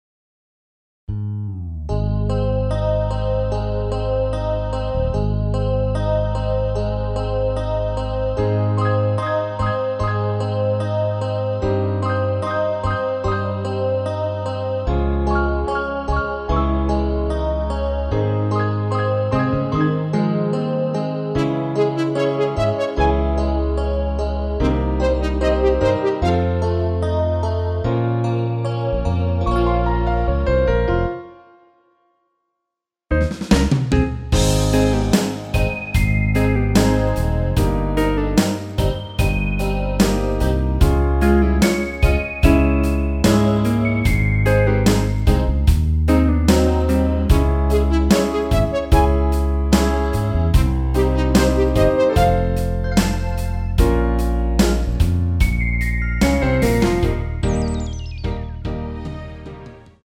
엔딩이 페이드 아웃이라 라이브하기 좋게 엔딩을 만들어 놓았습니다.
원키에서(-2)내린 MR입니다.
Ab
앞부분30초, 뒷부분30초씩 편집해서 올려 드리고 있습니다.